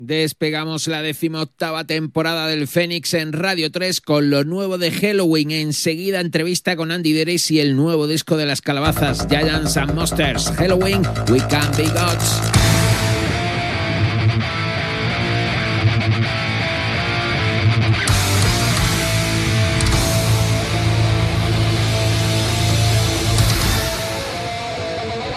Presentació en l'inici de la divuitena temporada i tema musical.
Musical